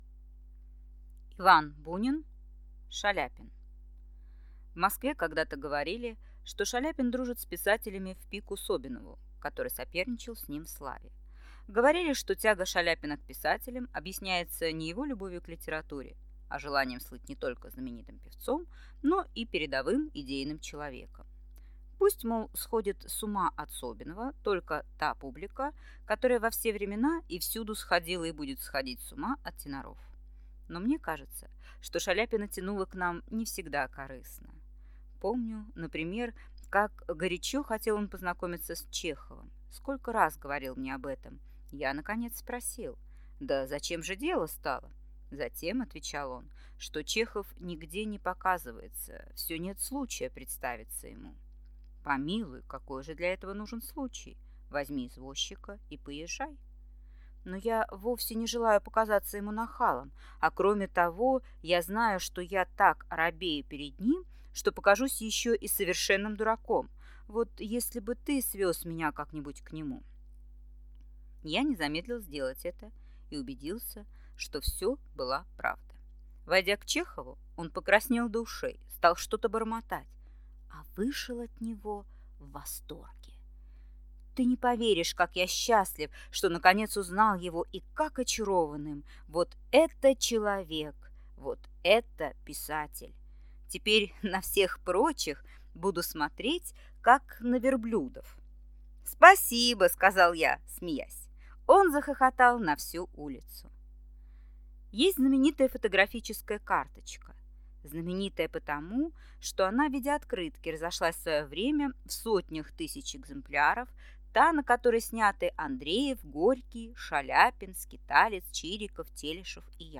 Аудиокнига Шаляпин | Библиотека аудиокниг